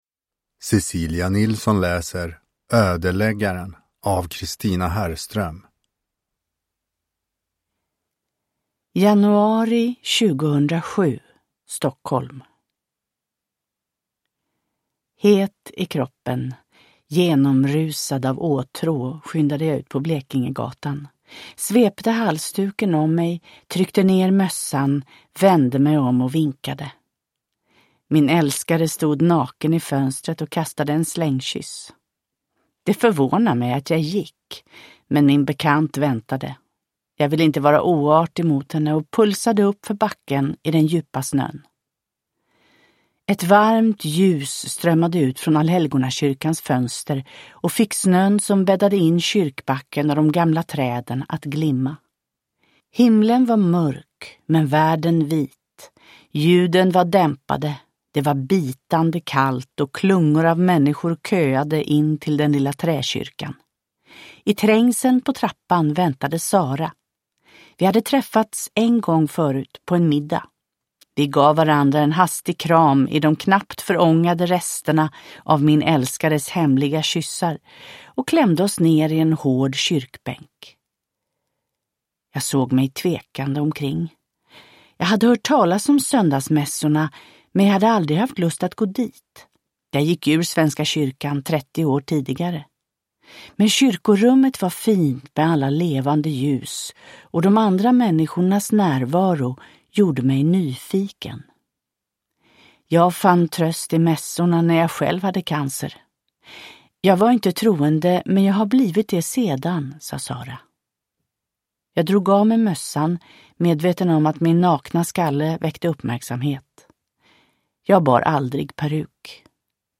Ödeläggaren – Ljudbok – Laddas ner
Uppläsare: Cecilia Nilsson